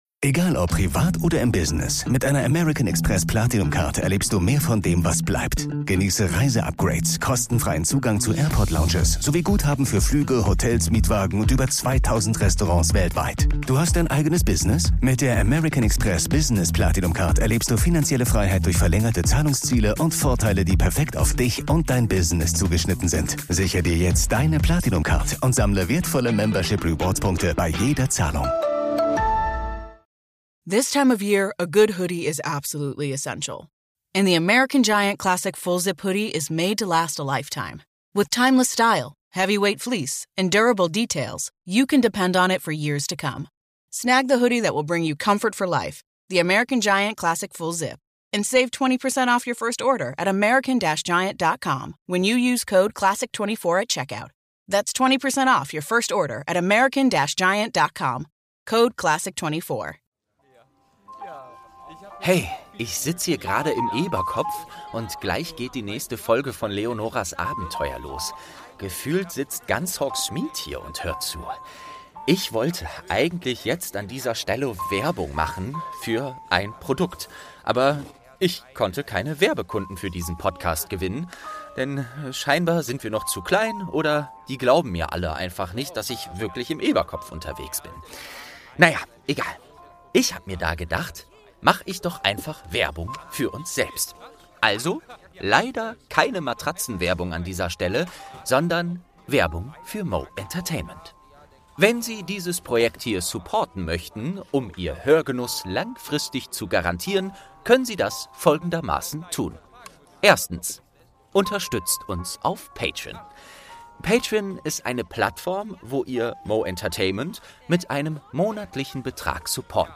10. Türchen | Magische Menagerie - Eberkopf Adventskalender ~ Geschichten aus dem Eberkopf - Ein Harry Potter Hörspiel-Podcast Podcast